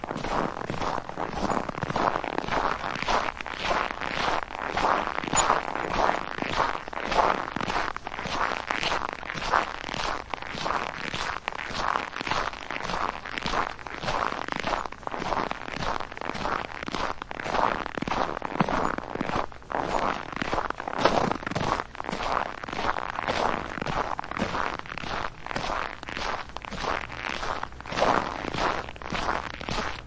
描述：走在我的院子里，薄薄的雪覆盖着脆脆的冰块。
Tag: 步行 cruncy